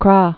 (krä), Isthmus of